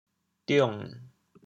「杜仲」用潮州話怎麼說？